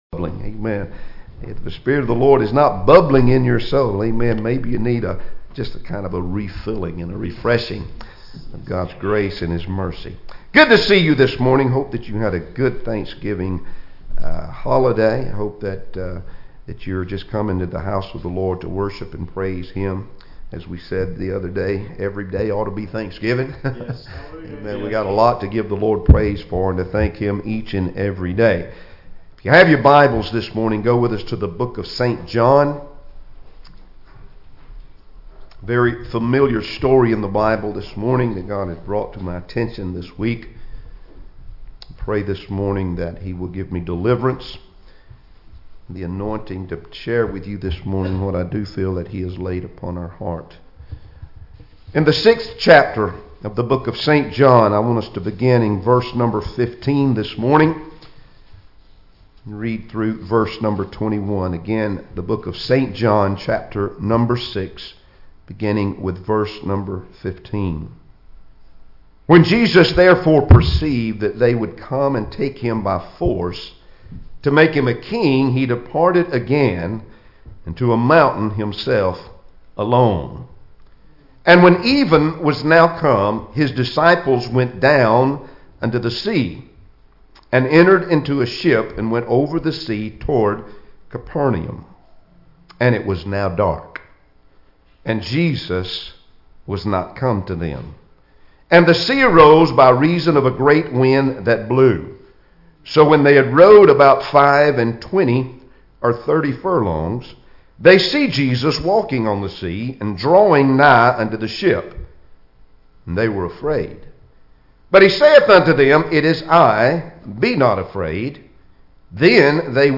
Passage: John 6:15-21 Service Type: Sunday Morning Services Topics